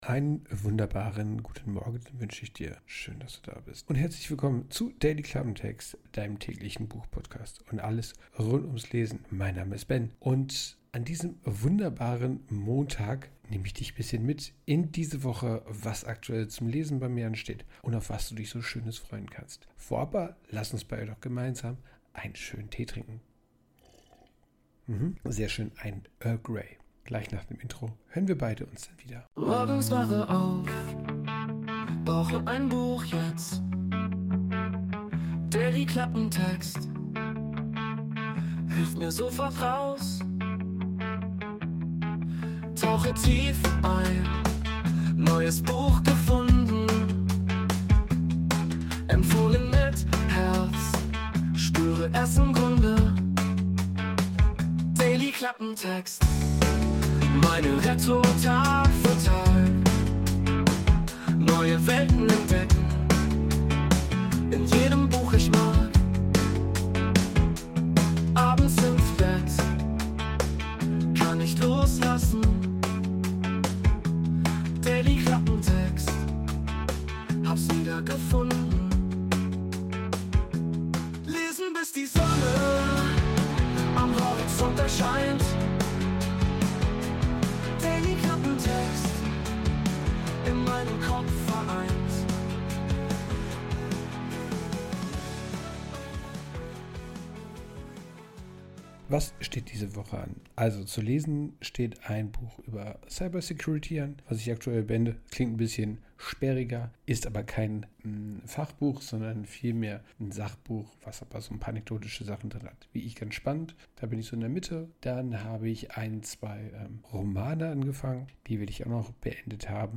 Intromusik: Wurde mit der KI Suno erstellt.